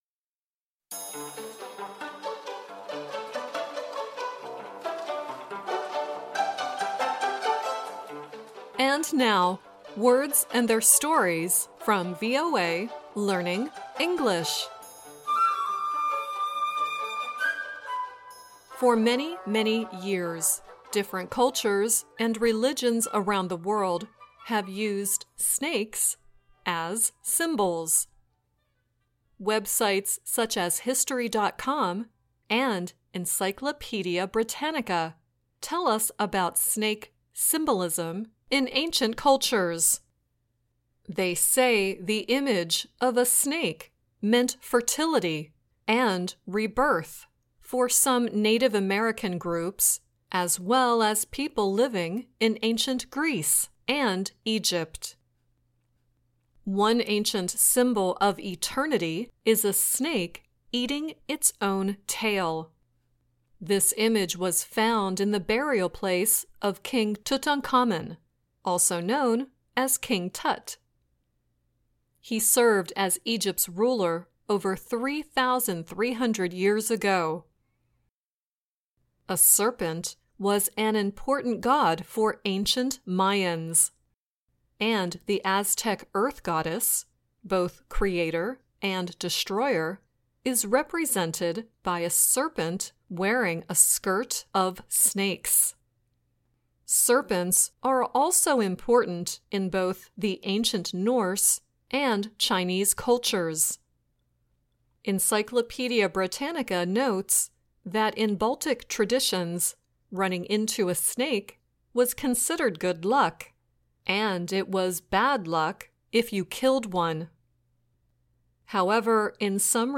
Kalau bisa diusahakan bacanya mirip dengan native speaker dibawah ini, oke..
The song at the end is “Dear Hate” sung by Maren Morris and Vince Gill.